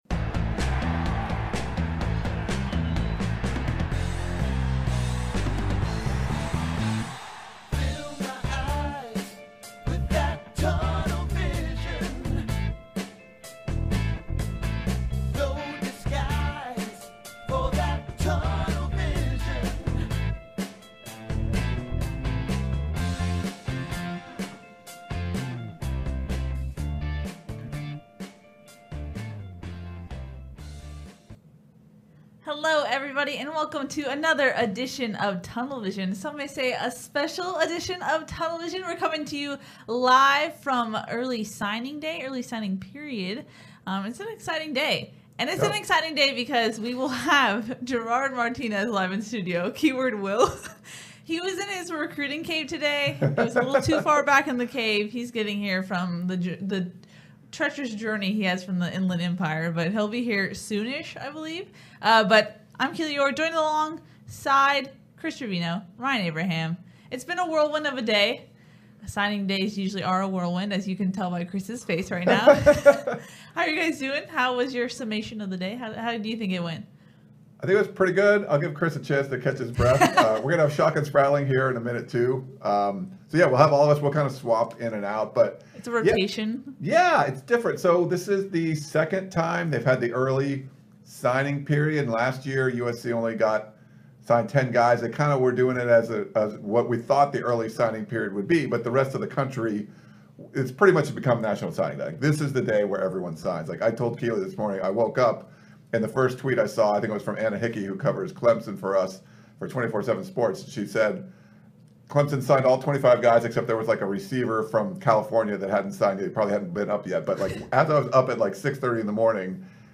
We had a huge LIVE Tunnel Vision show
all in-studio to talk about USC's haul on the Early Signing Period.